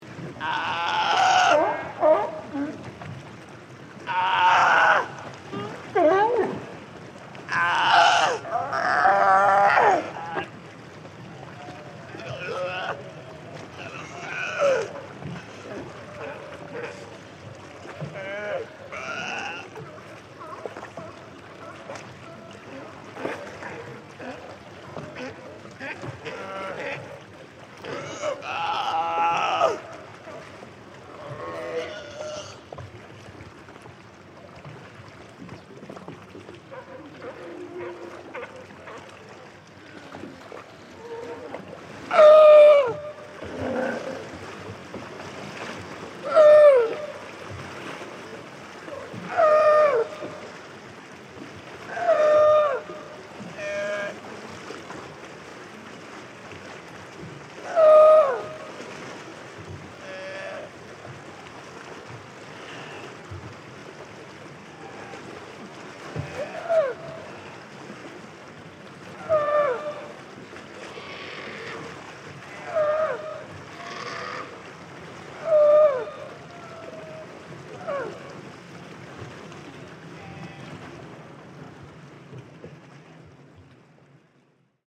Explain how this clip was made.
Recorded from boat. Stereo 48kHz 24Bit.